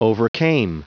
Prononciation du mot overcame en anglais (fichier audio)
Prononciation du mot : overcame